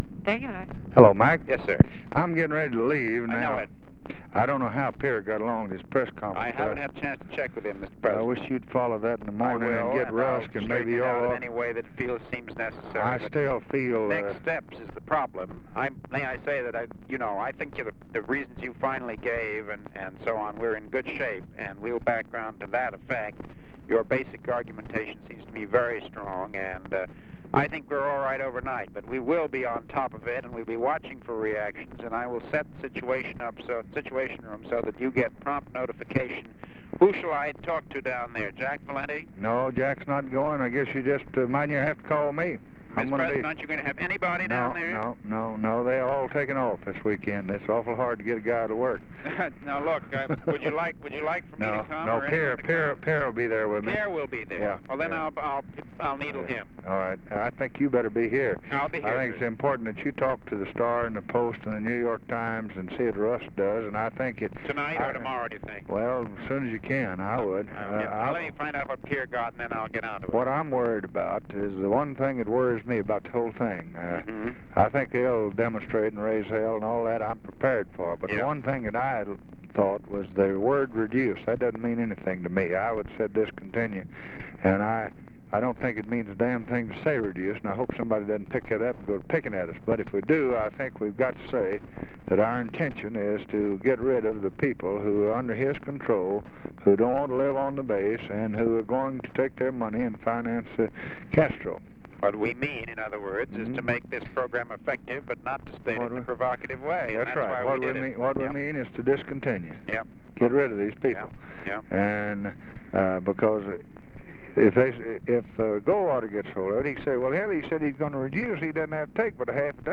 Conversation with MCGEORGE BUNDY, February 8, 1964
Secret White House Tapes